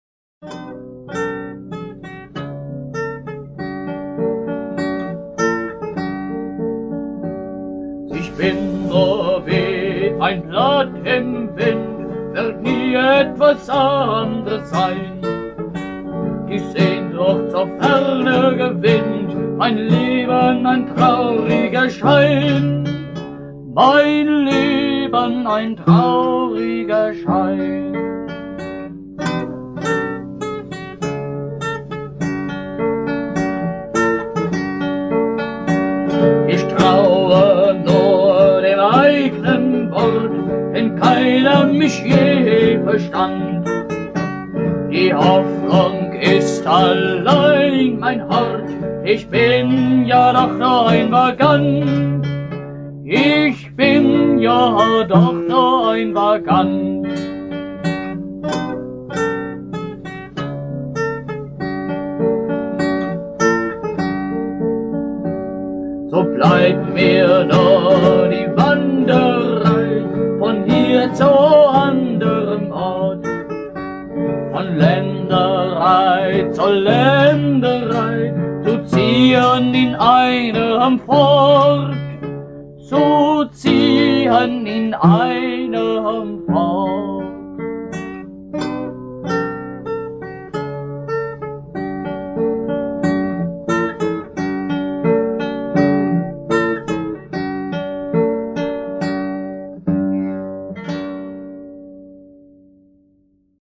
Meine frühen Lieder